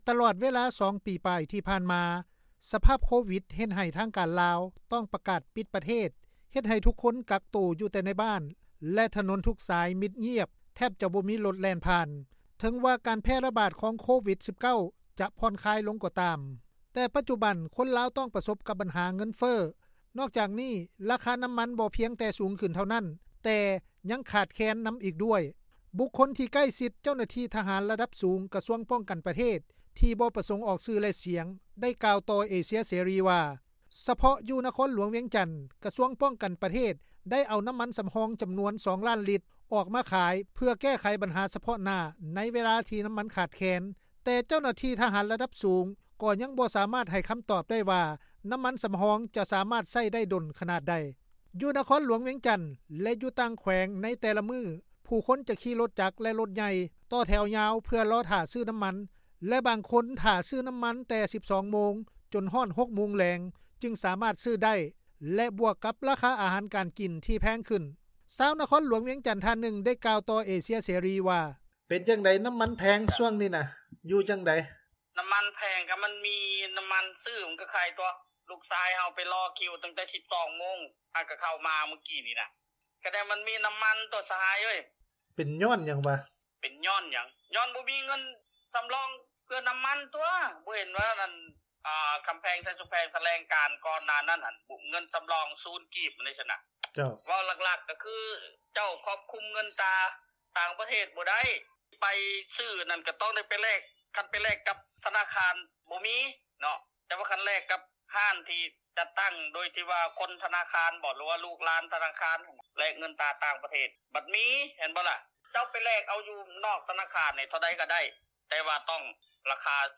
ຊາວນະຄອນຫລວງວຽງຈັນ ທ່ານຶ່ງ ໄດ້ກ່າວຕໍ່ວິທຍຸເອເຊັຽເສຣີ ວ່າ:
ປະຊາຊົນ ແຂວງໄຊຍະບູຣີ ໄດ້ກ່າວໃຫ້ສຳພາດຕໍ່ເອເຊັຽເສຣີ ວ່າ: